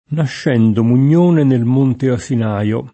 m1nte aSin#rLo]; e prima, Asinaro [aSin#ro] e Asinaio [aSin#Lo]: Sacro, superbo, altissimo Asinaro [S#kro, Sup$rbo, alt&SSimo aSin#ro] (Varchi); nascendo Mugnone nel monte Asinaio [